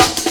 amen pt-3 snare.wav